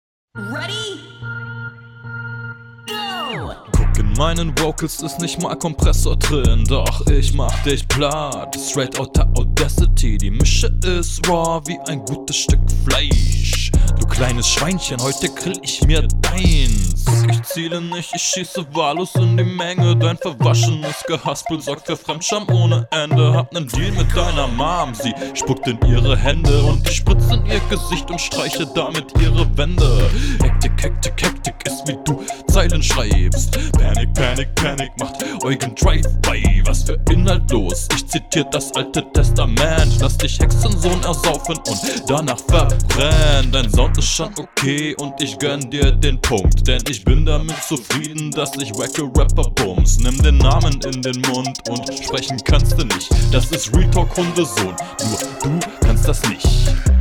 find deinen stimmeinsatz iwie funny also attitude is cool